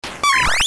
Squeak.ogg